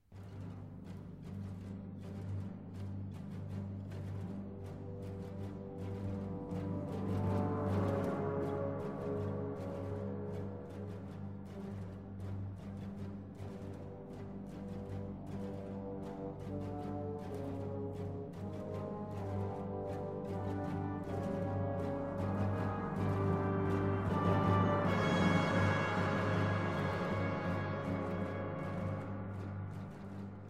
You can hear two harps in the clip above.